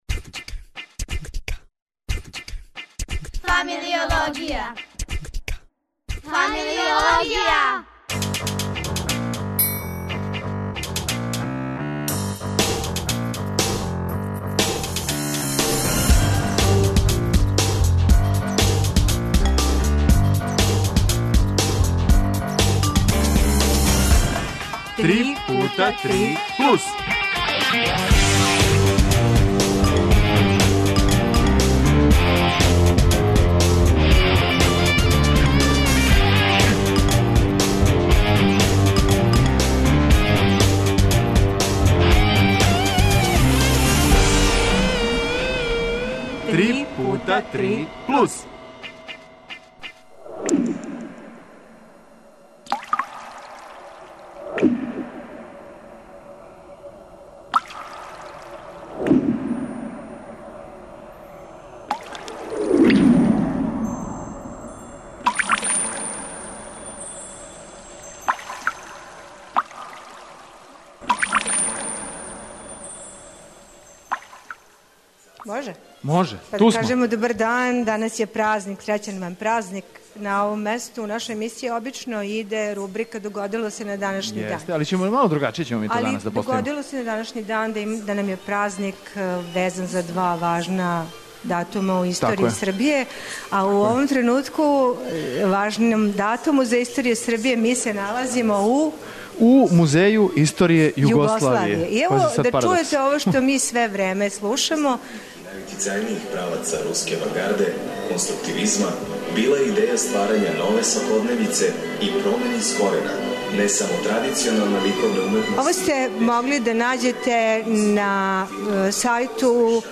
Одговори у емисији из Музеја историје Југославије, са изложбе Руска авангарда....